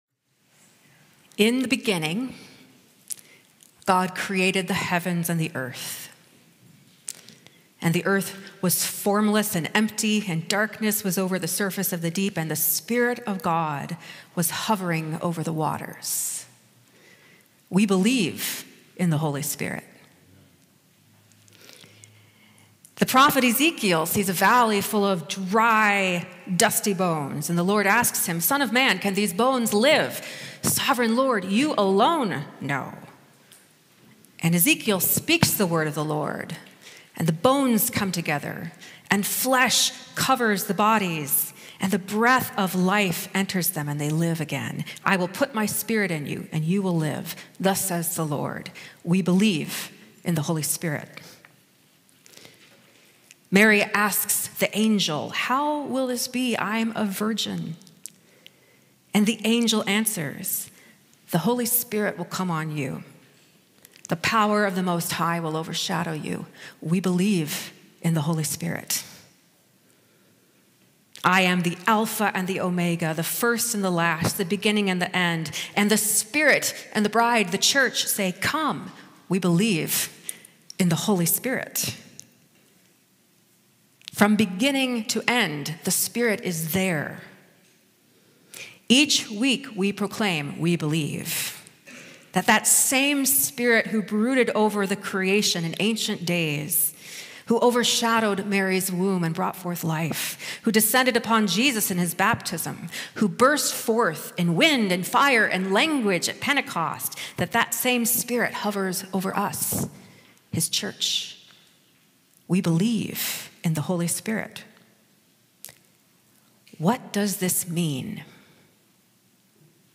Sermon -